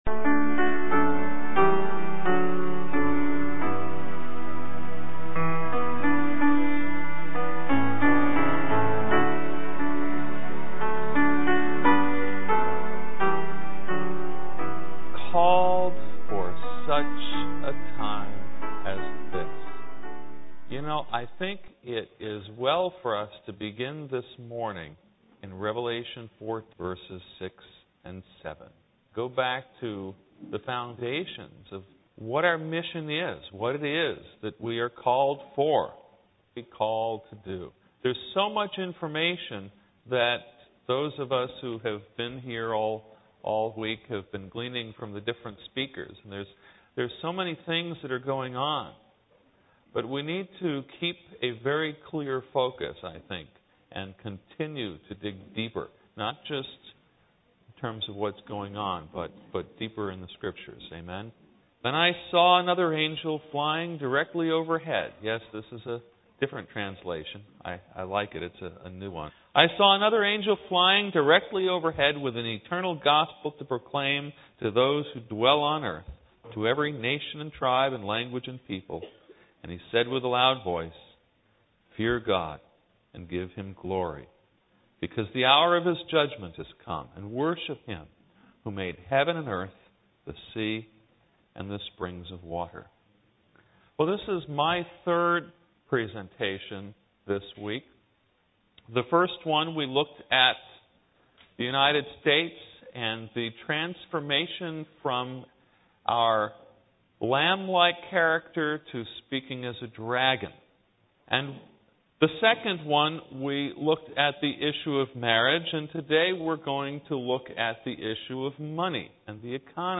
Esq. at the 2009 Northwest Religious Liberty Symposium sponsored by the North Pacific Union Conference and hosted by Laurelwood Academy.